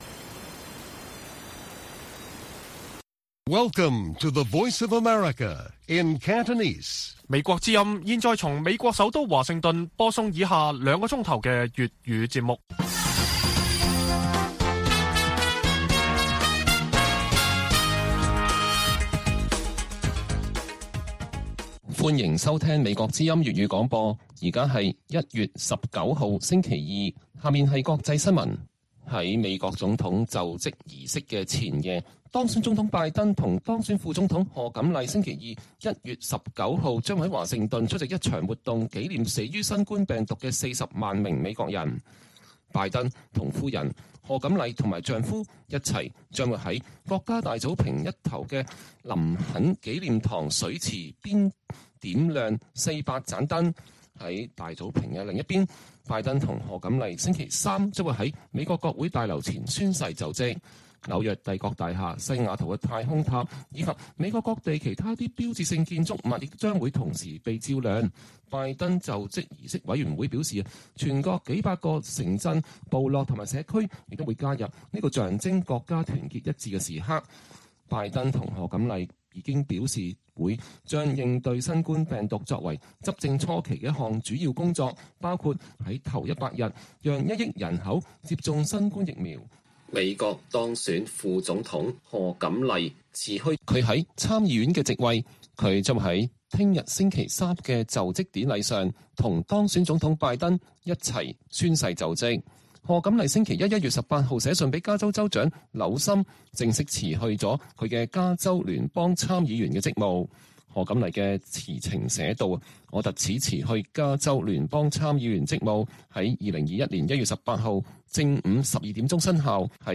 粵語新聞 晚上9-10點